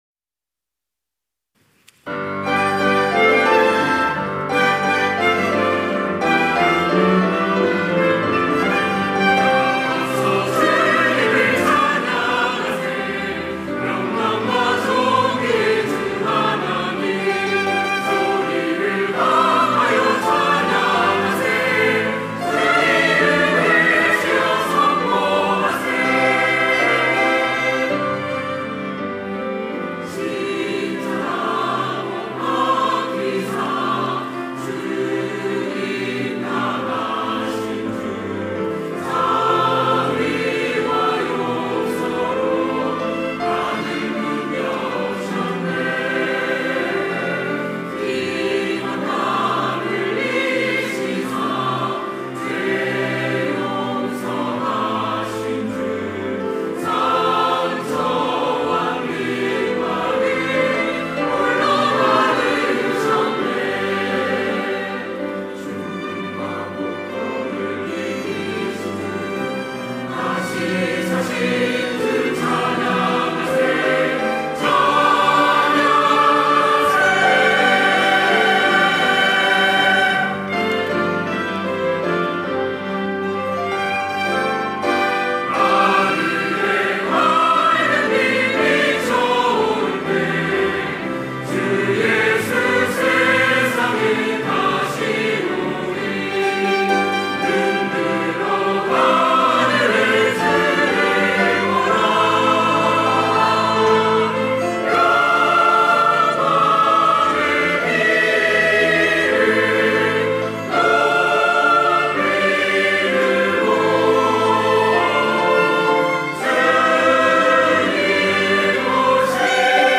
호산나(주일3부) - 다 와서 주님을 찬양하세
찬양대